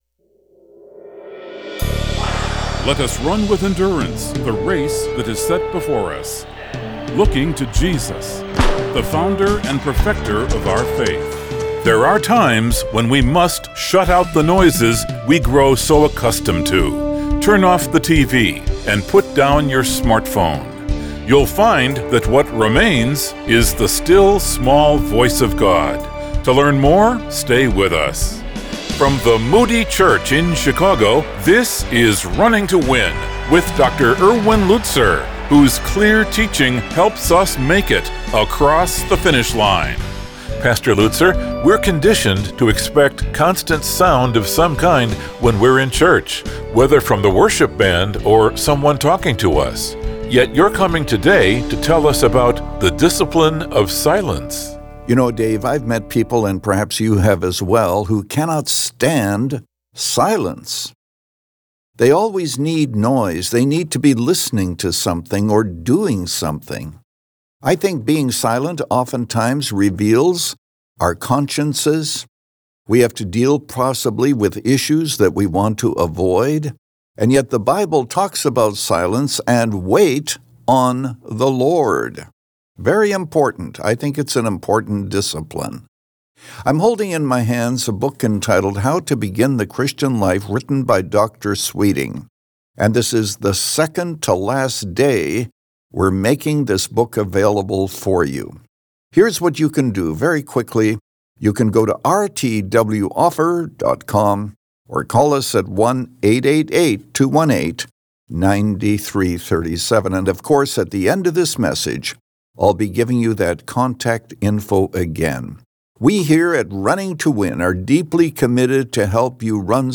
But with the Bible front and center and a heart to encourage, Pastor Erwin Lutzer presents clear Bible teaching, helping you make it across the finish line. Since 2011, this 25-minute program has provided a Godward focus and features listeners’ questions.